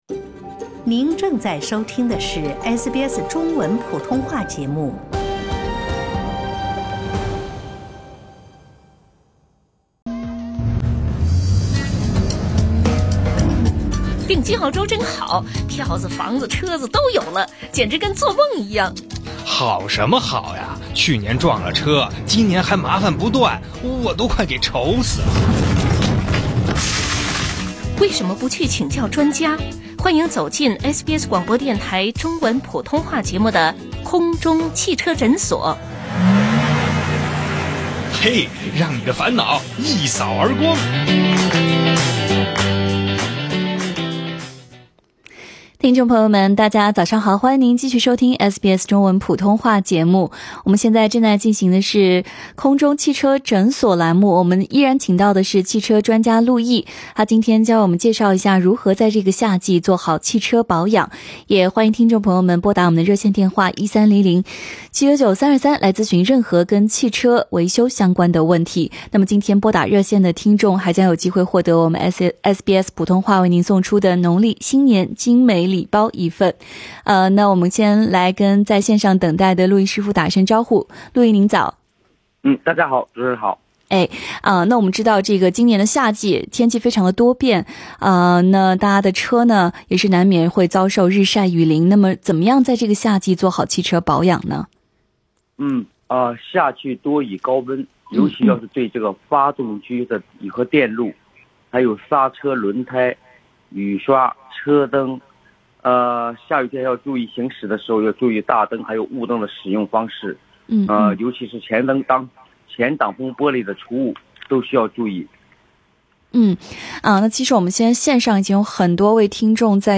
今天拨打热线的听众还将有机会获得我们SBS普通话为您送出的农历新年精美礼包。